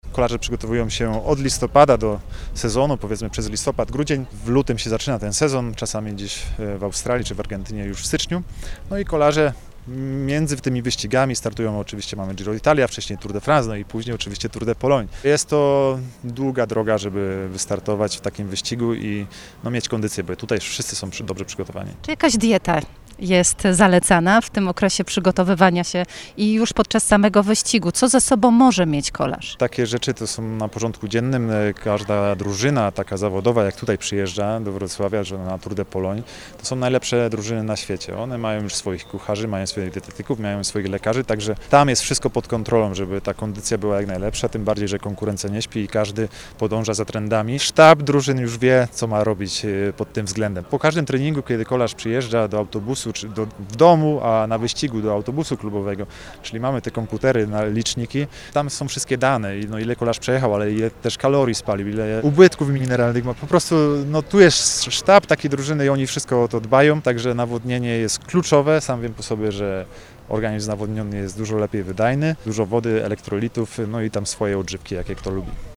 Tour de Pologne - konferencja prasowa
Taki wyścig wymaga odpowiedniej formy i przygotowania kolarskiego, podkreśla Maciej Bodnar, wielokrotny mistrz Polski w kolarstwie szosowym, wicemistrz Europy, mieszkaniec Wrocławia.